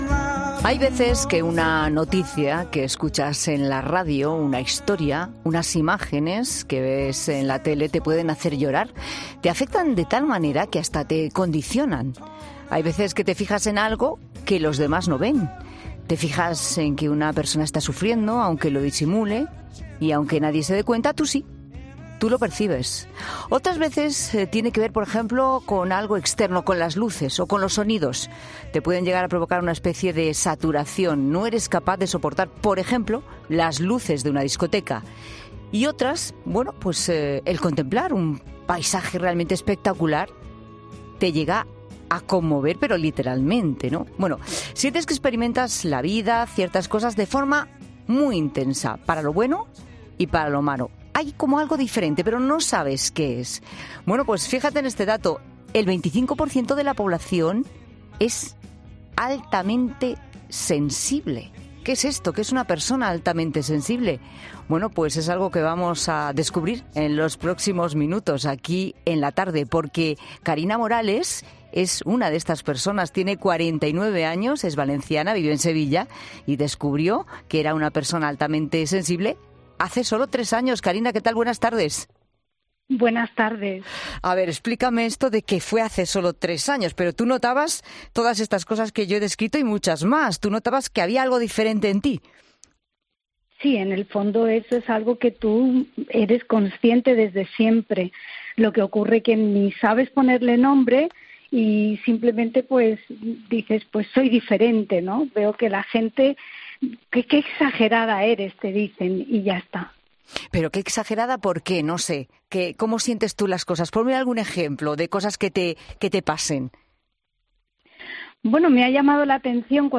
AUDIO: El 25 por ciento de la población es altamente sensible y en 'La Tarde' hemos hablado con tres personas que sufren este tipo de afección